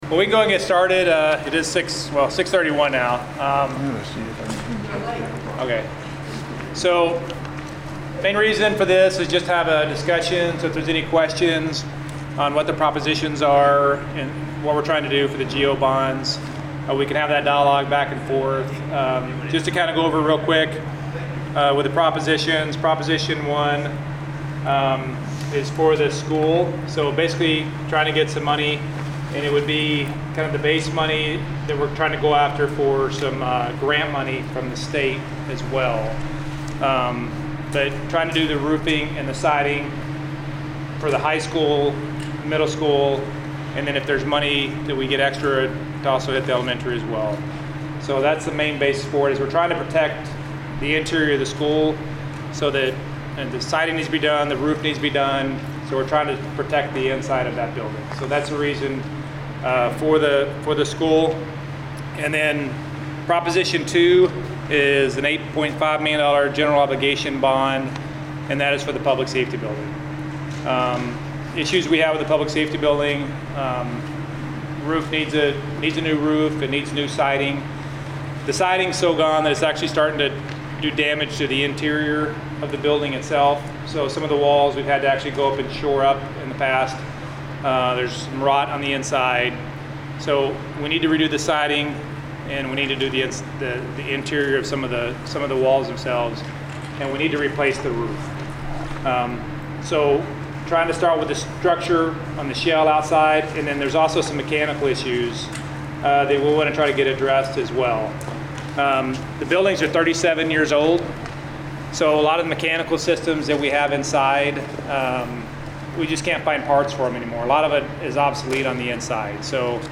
09.30-FULL-TOWN-HALL.mp3